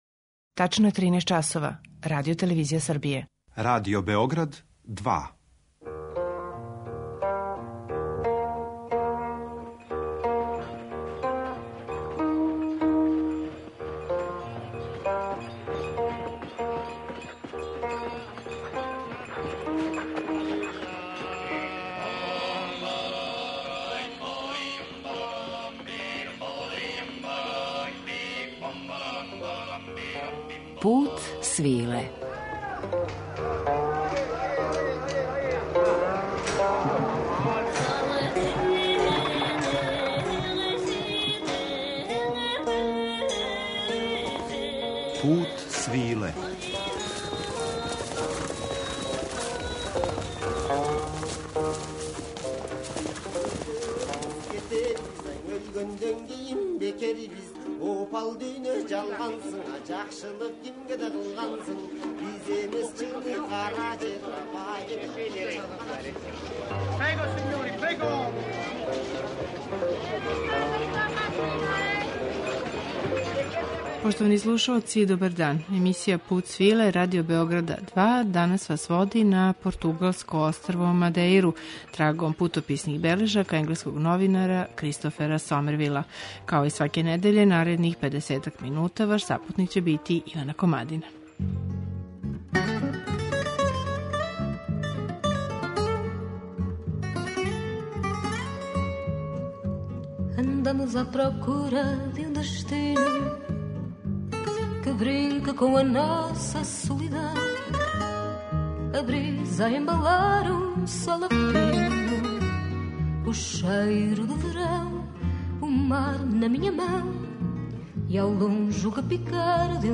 Oбилазимо леваде на Мадеири, уз мајсторе новог фада